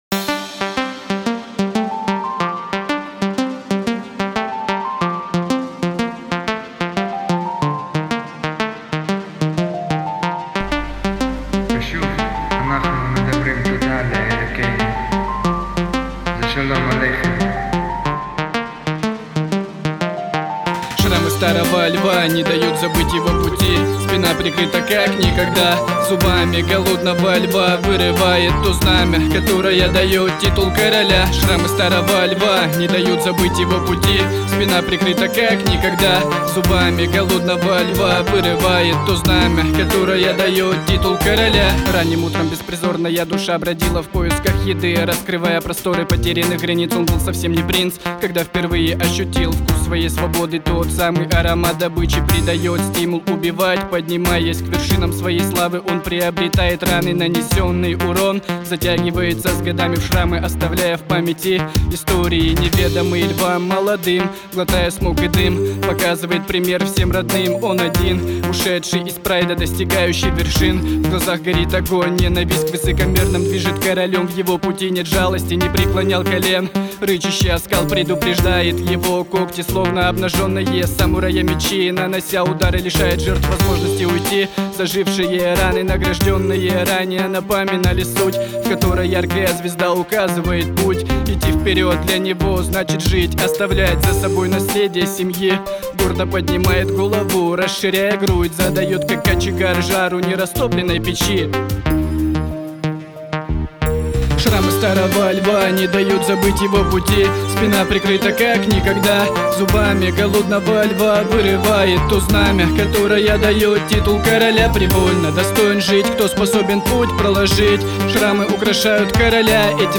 Рэп музыка